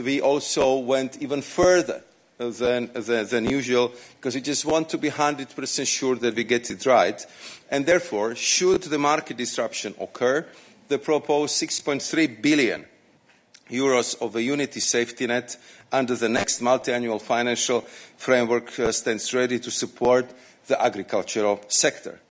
EU Trade Commissioner Maroš Šefčovič, insists they have acted on farmers concerns………………..